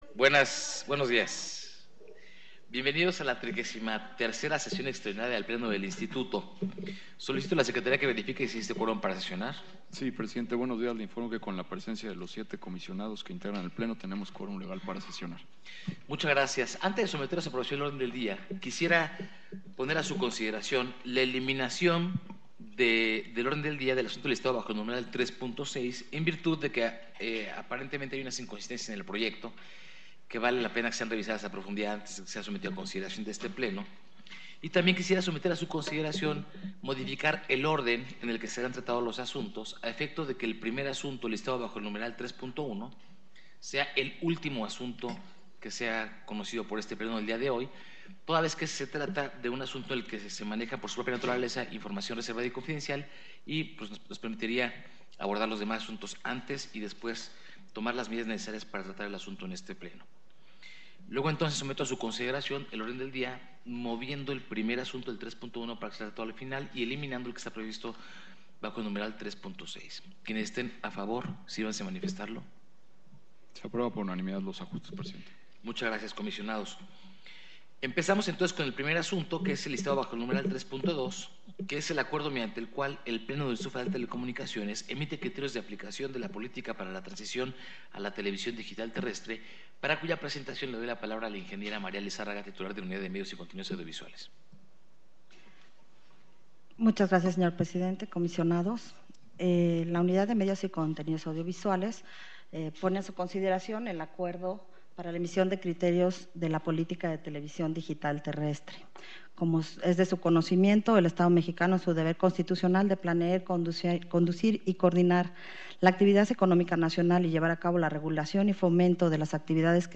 XXXIII Sesión Extraordinaria del Pleno 30 de septiembre de 2015 | Comisión Reguladora de Telecomunicaciones - IFT